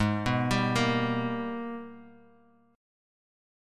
G#mM9 Chord
Listen to G#mM9 strummed